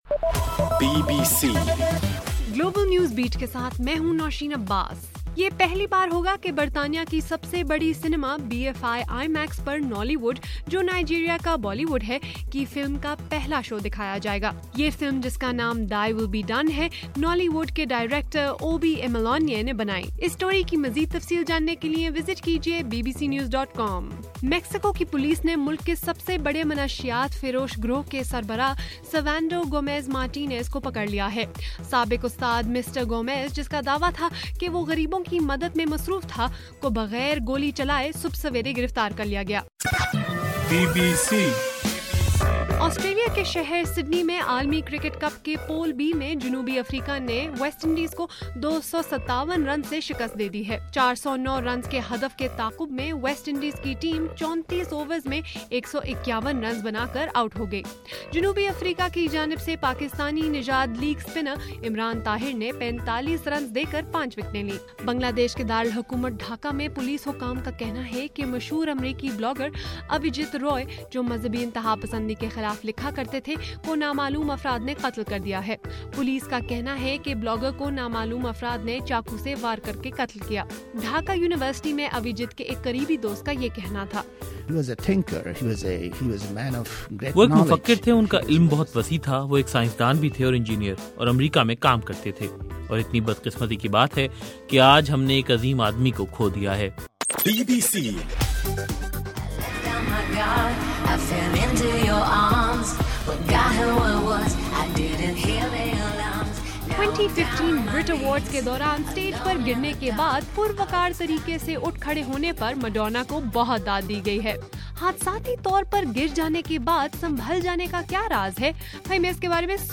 فروری 27: رات 12 بجے کا گلوبل نیوز بیٹ بُلیٹن